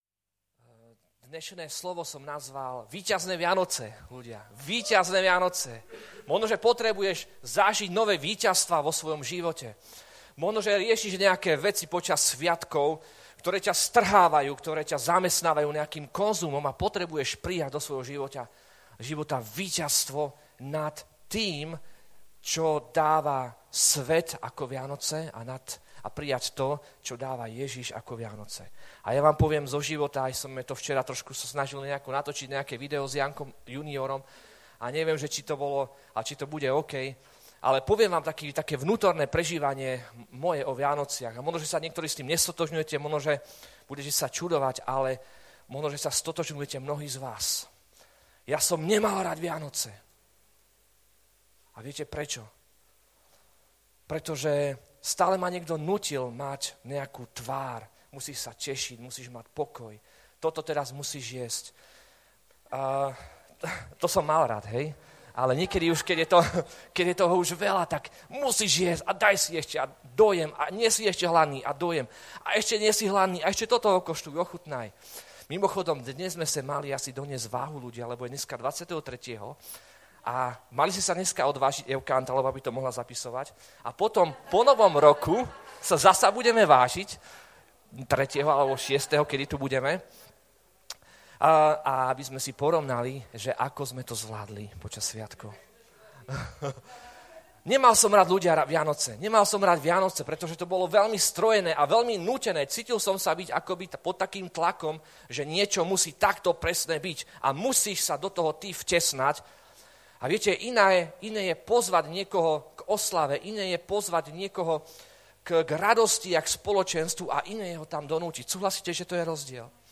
Vydané: 2018 Žáner: kázeň